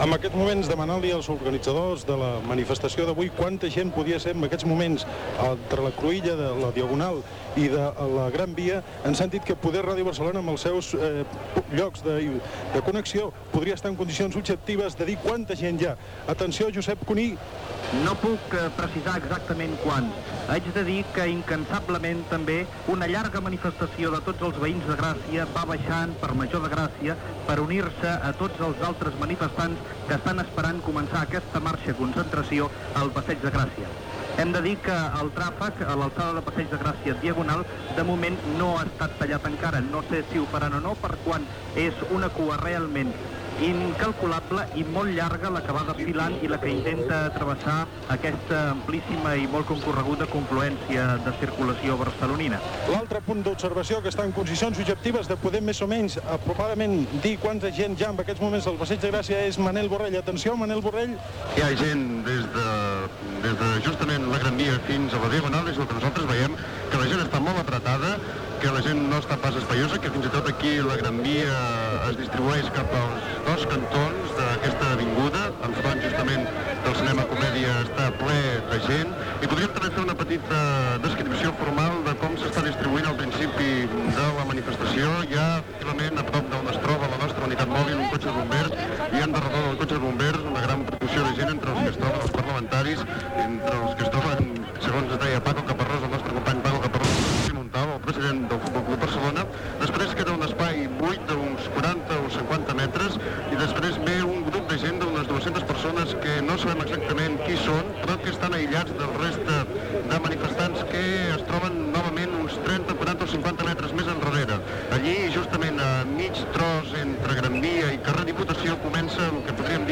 Fermín Bocos dona pas a diverses connexions abans que comenci la manifestació per l'Estatut de 1977, coincidint amb la Diada Nacional de Catalunya de l'11 de setembre de 1977. Els periodistes descriuen l'ambient i l'assistència
Informatiu